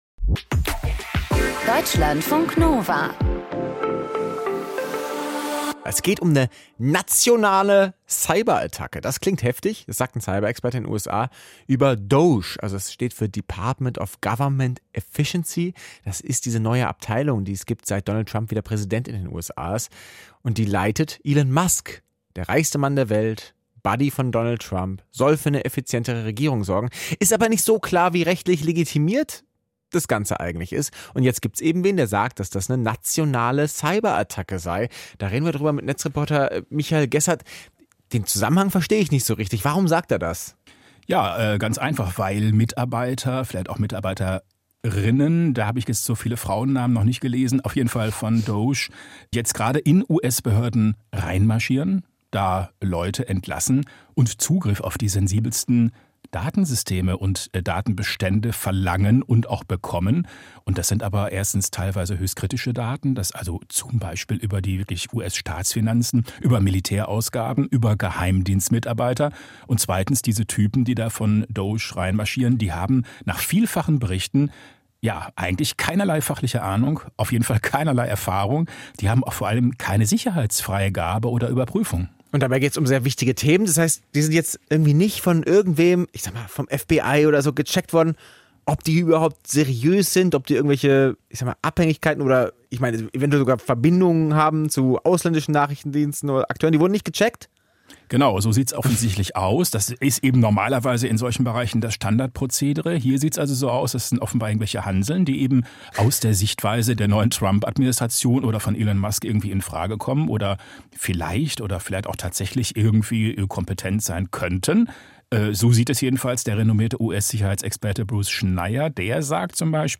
Kommentar zu US-Leak aus Signal-Chat: Vorgebliche Effizienz